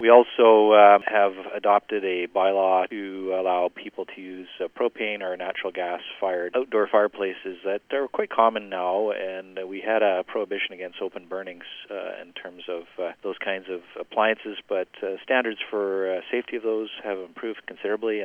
City council has approved a new bylaw allowing the use of outdoor natural gas or propane fireplaces. Comox Mayor Paul Ives says the safety standards of these products have improved.